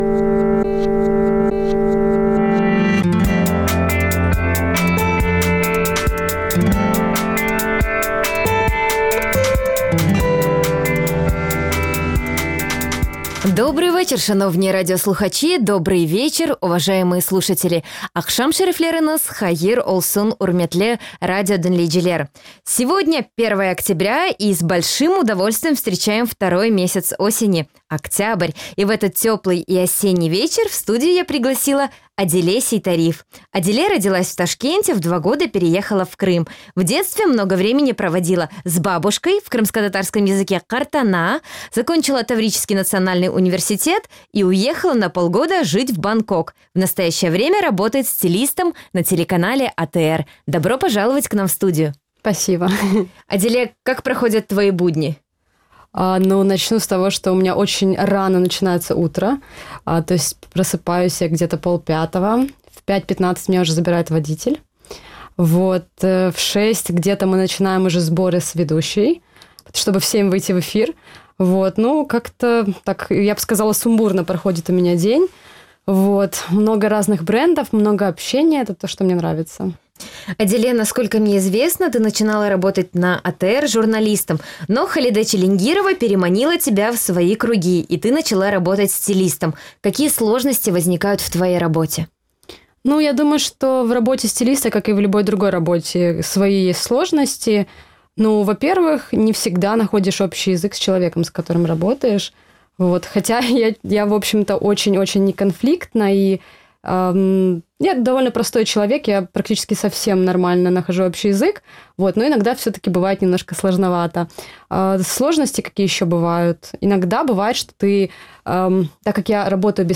Программа звучит в эфире Радио Крым.Реалии. Это новый, особенный формат радио.